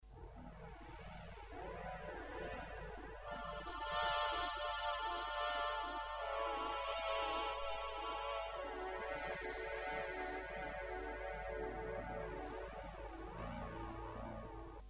Class: Synthesizer